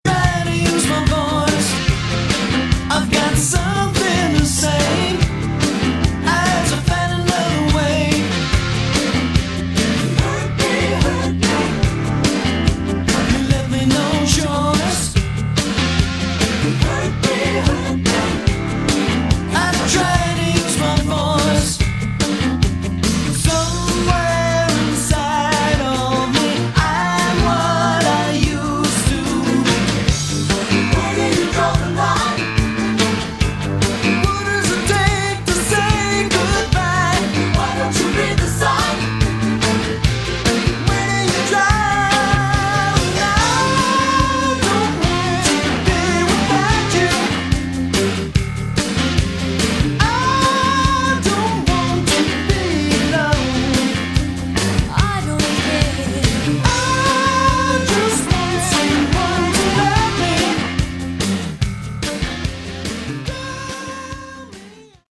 Category: Hi-Tech AOR